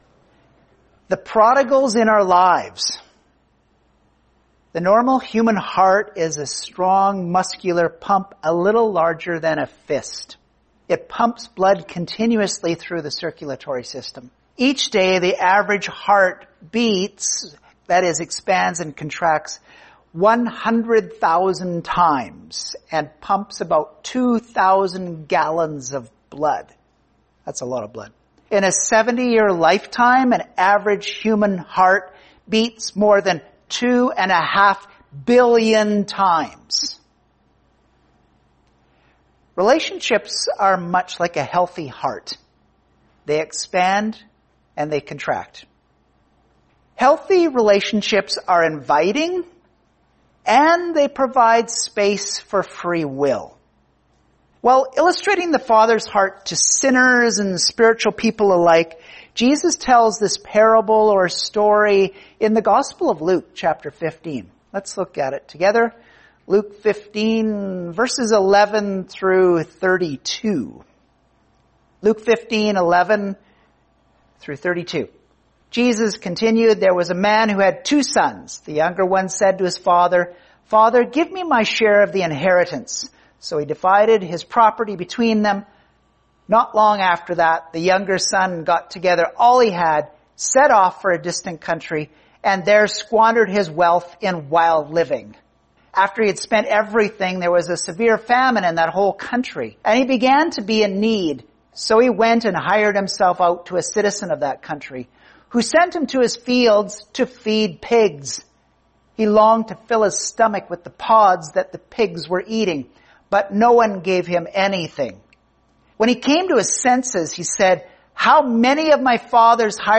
Sermons | Project 412